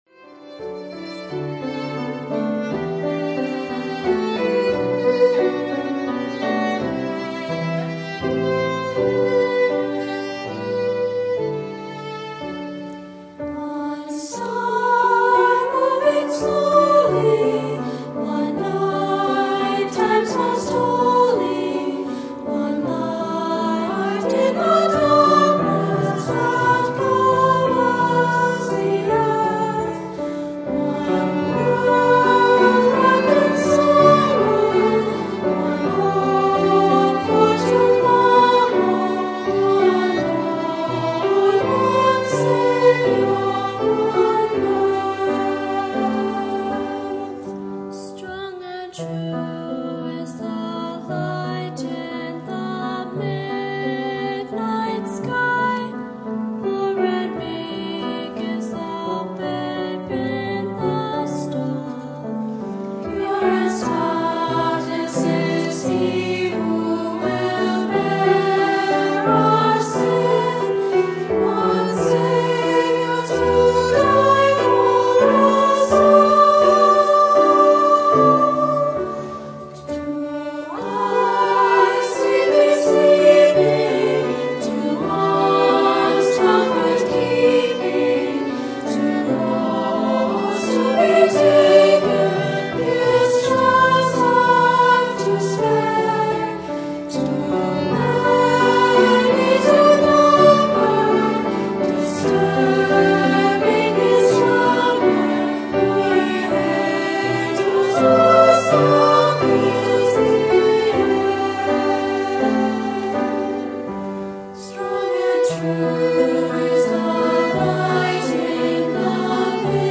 2-part choir, piano, violin